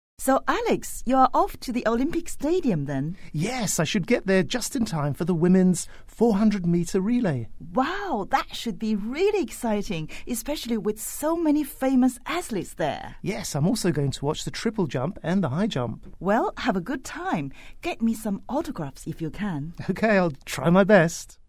english_47_dialogue_2.mp3